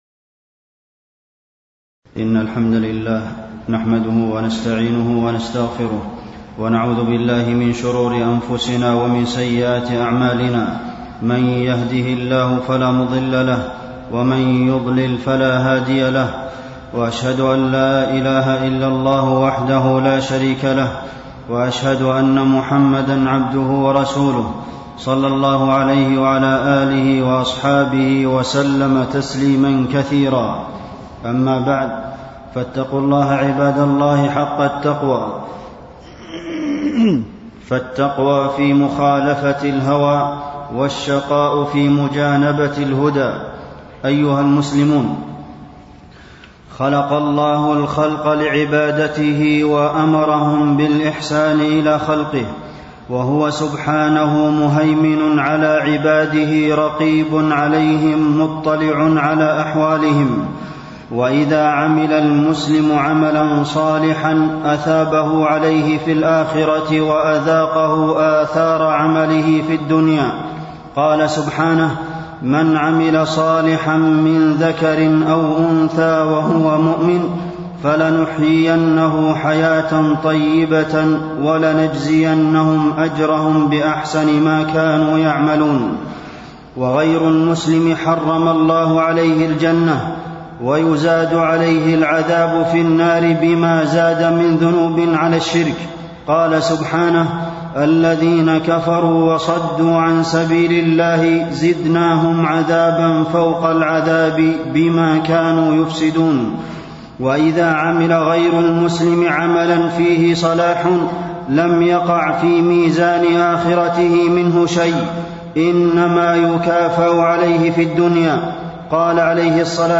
تاريخ النشر ٥ صفر ١٤٣٣ هـ المكان: المسجد النبوي الشيخ: فضيلة الشيخ د. عبدالمحسن بن محمد القاسم فضيلة الشيخ د. عبدالمحسن بن محمد القاسم الجزاء من جنس العمل